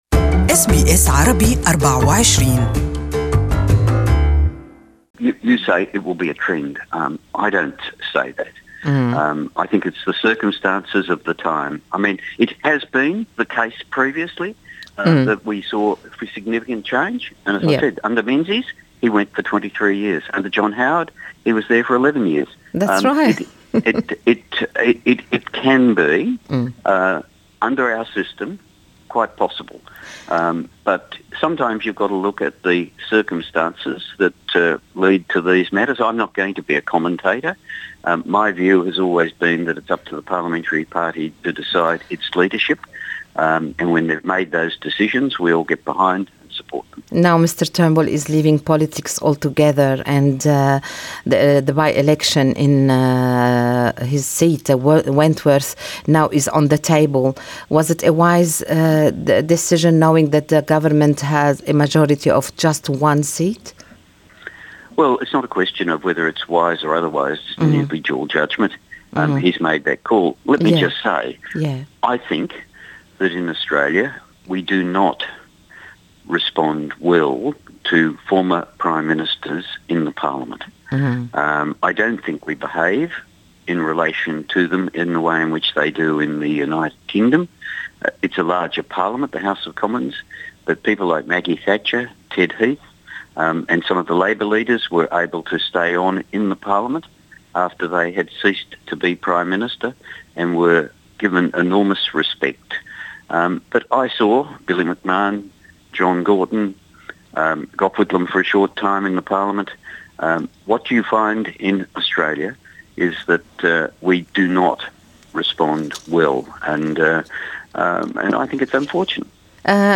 Listen to the full interview in English above.